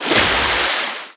Sfx_creature_penguin_dive_shallow_01.ogg